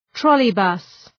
Προφορά
{‘trɒlıbʌs}